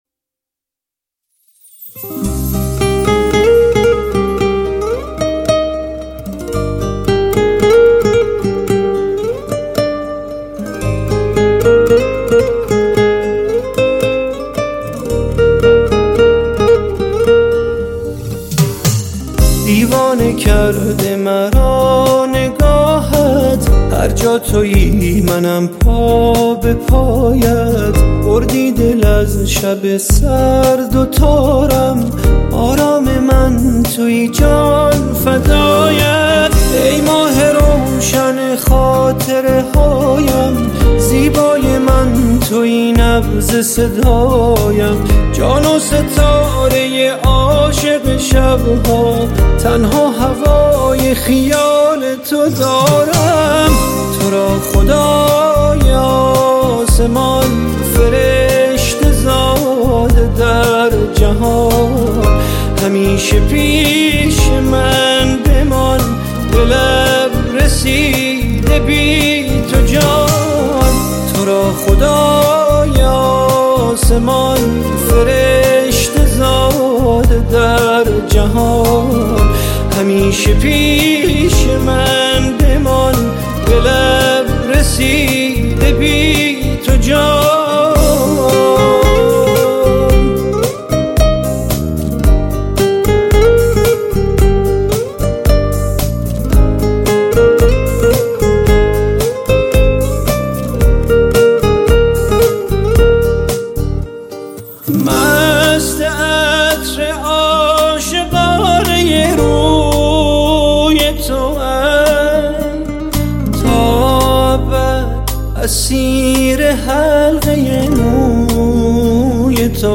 سبک : موسیقی پاپ